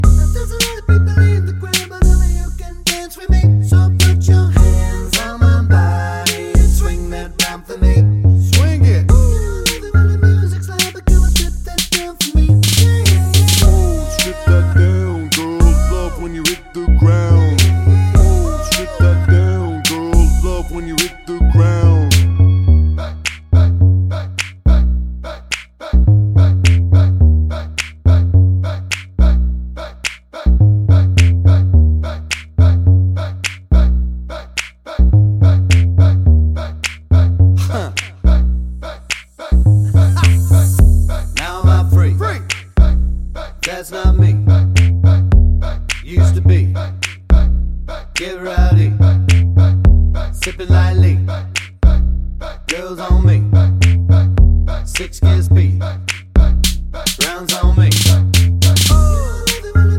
for duet Pop (2010s) 3:23 Buy £1.50